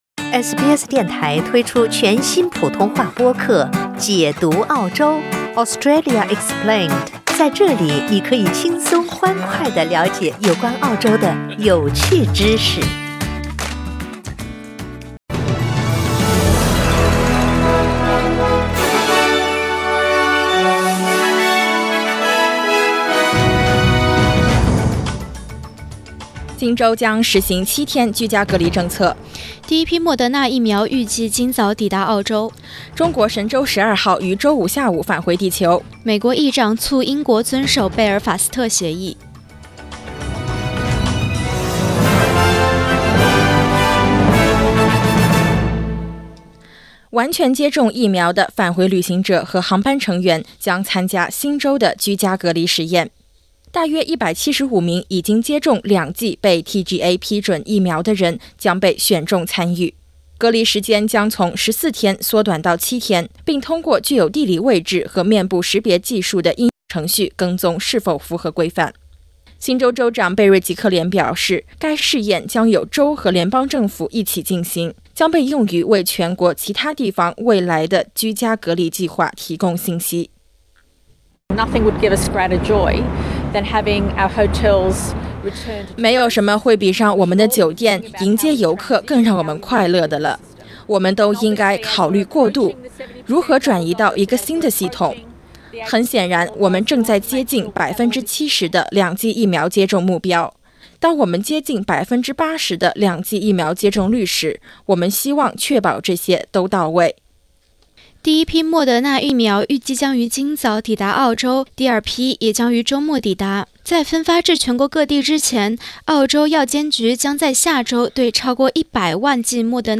SBS早新聞（9月18日）